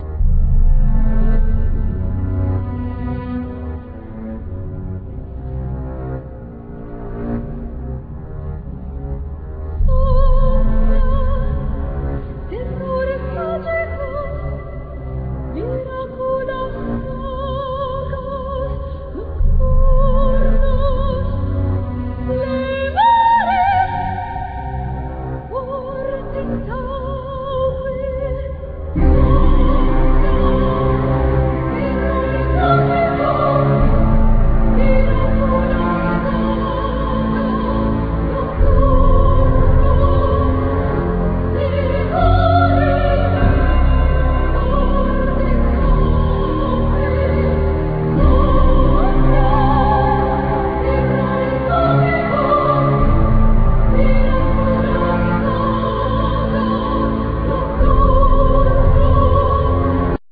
Voice,Keyboards,Percussions
Keyboards,Voice,Bass,Programming
Flute
Oboe
Percussions,Ocean drums
Violin